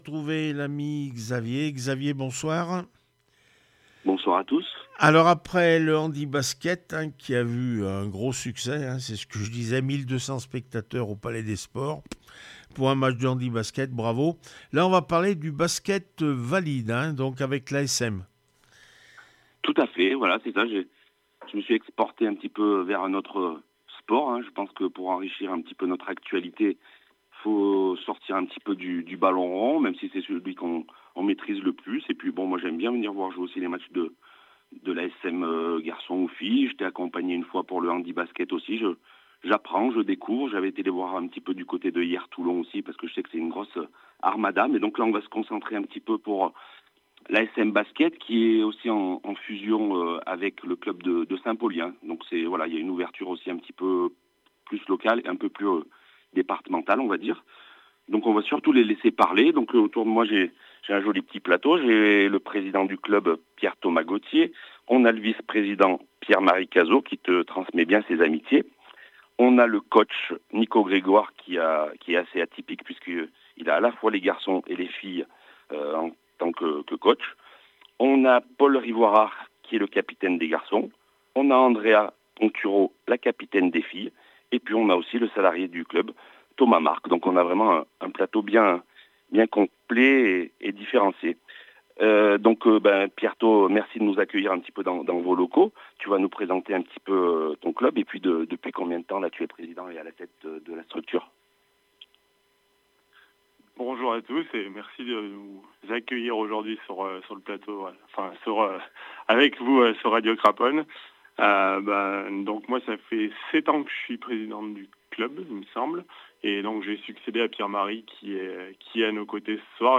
asmb le puy basket entretien